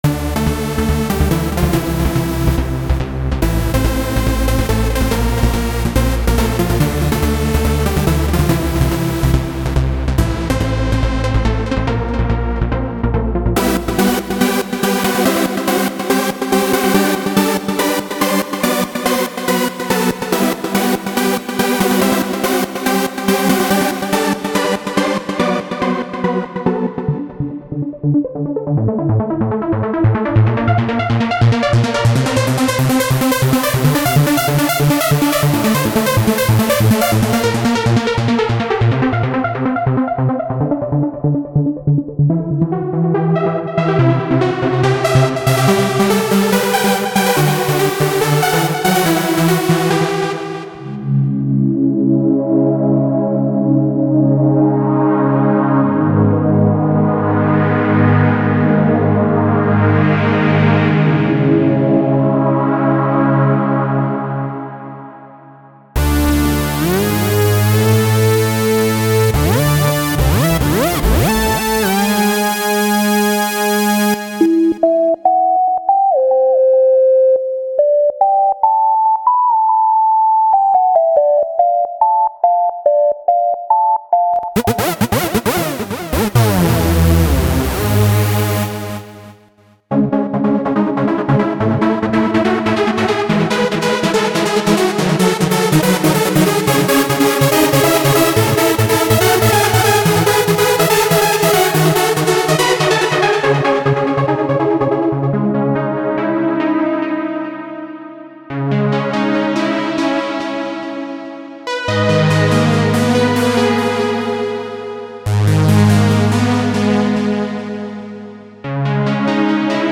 - Mod wheel is hardwired to the cutoff frequency.